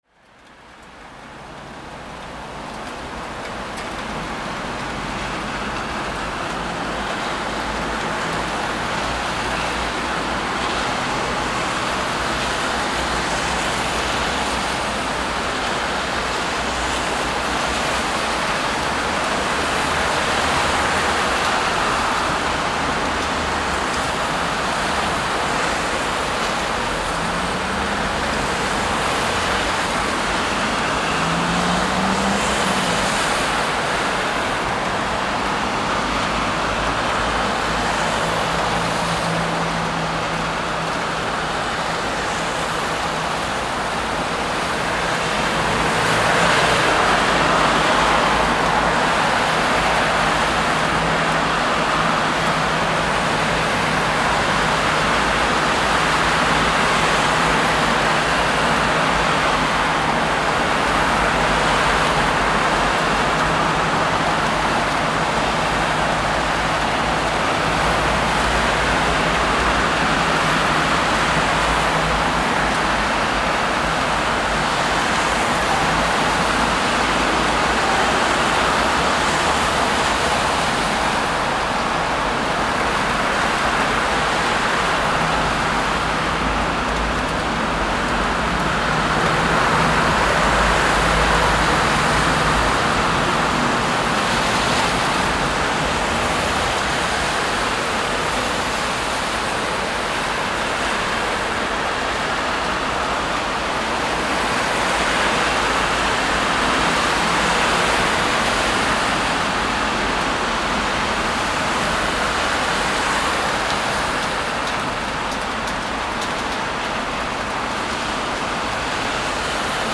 City Traffic In The Rain Sound Effect
Authentic recording of city traffic in the rain. You can hear the splashing of tires on wet asphalt and raindrops hitting a building’s window ledge. Perfect ambient soundscape for film, video, games, or urban scenes.
City-traffic-in-the-rain-sound-effect.mp3